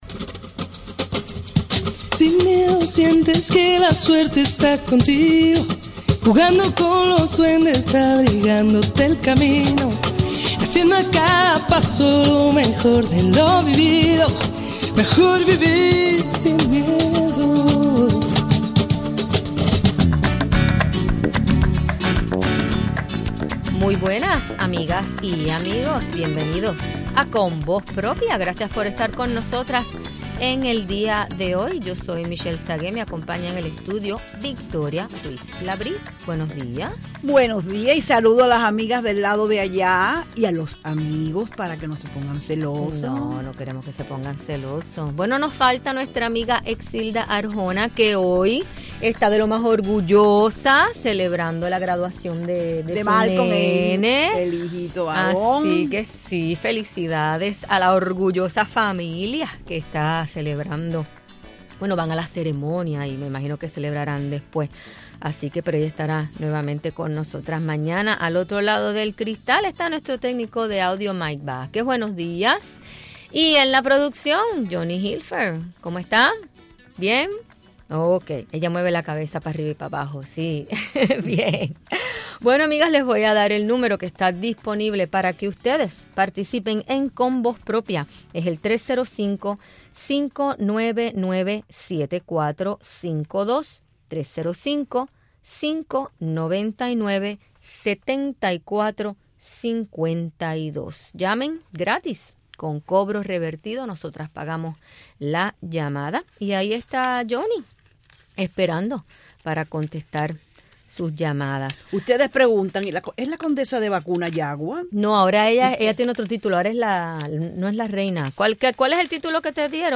Entrevista a activistas de FLAMUR sobre sus experiencias en Miami y la situacion de la mujer cubana